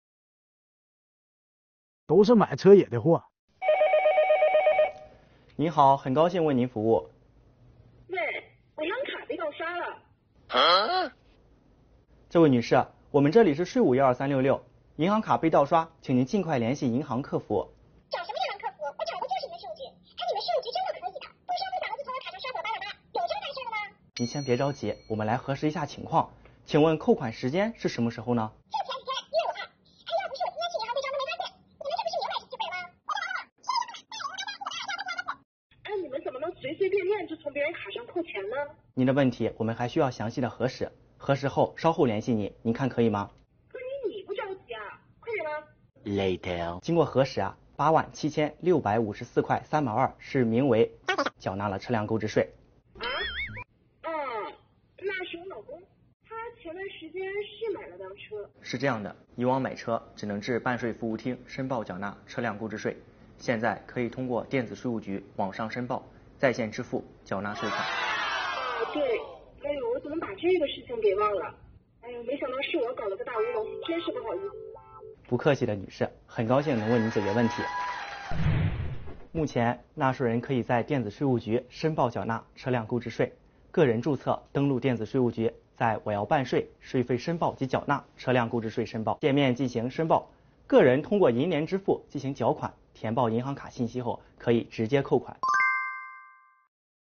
12366咨询员接到电话，电话那头传来劈头盖脸的“责骂声”，经过咨询员的耐心核实，原来是买车搞的“大乌龙”。咨询员同时讲解了车购税的网上申报流程，结局很欢喜。
作品虽然故事短小、场景简单，但是情节富有转折、叙事节奏强，人物表演真实生动，用极具生活化的语言还原了办税场景，普及了税收知识，有趣有料。